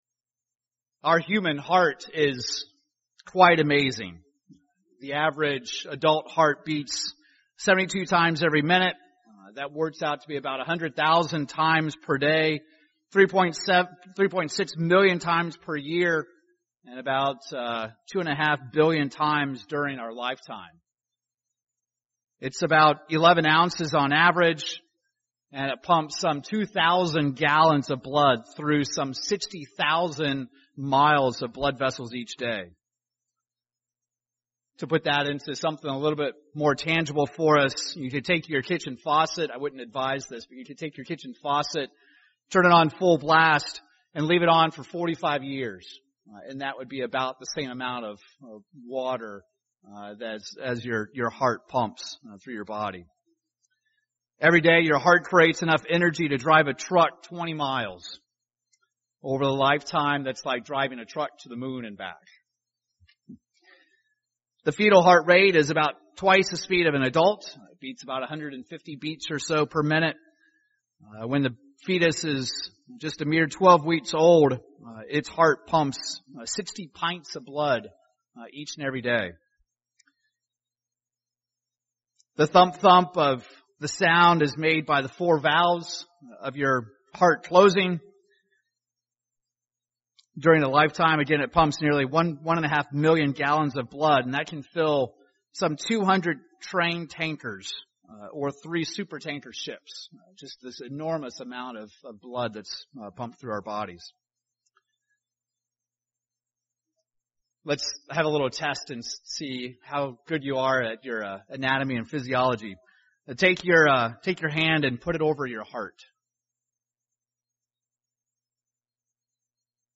Given in Tulsa, OK
UCG Sermon Studying the bible?